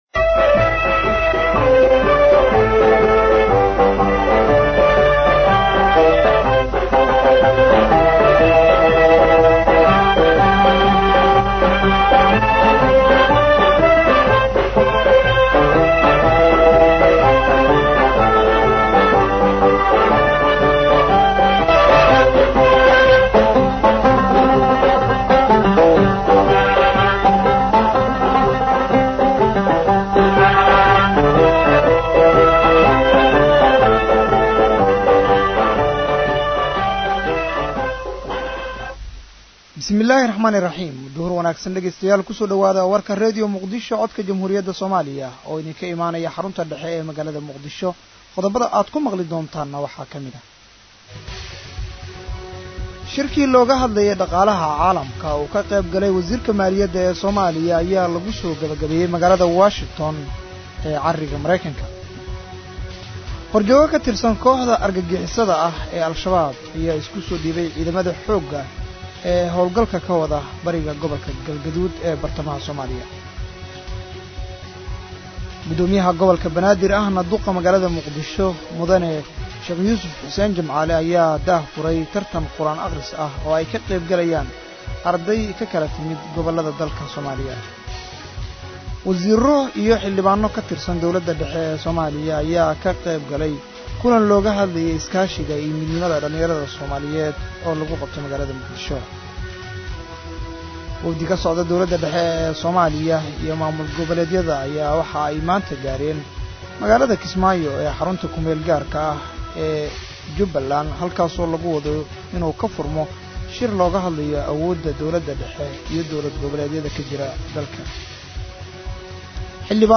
Dhageyso Warka Duhur ee Radio Muqdisho